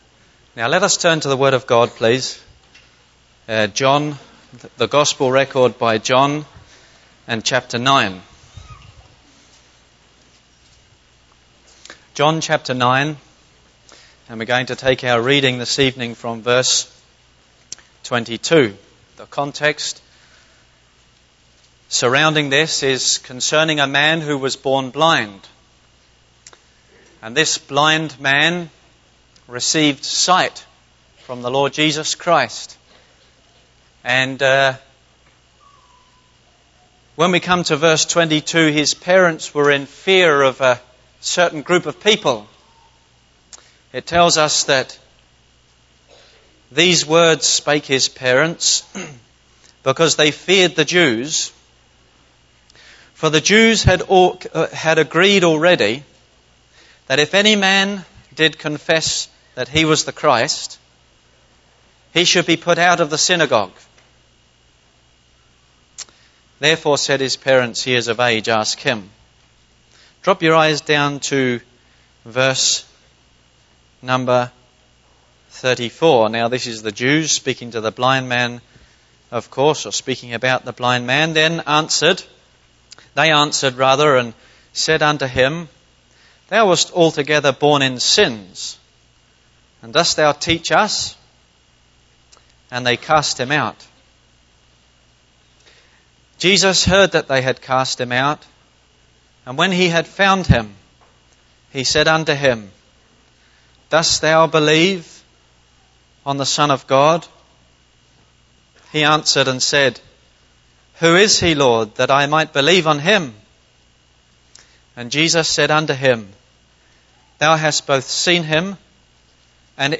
Series: Sarnia Gospel Hall Conference 2015 Service Type: Gospel Preaching Topics: Testimony